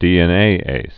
(dēĕn-āās)